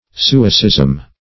Suicism \Su"i*cism\, n.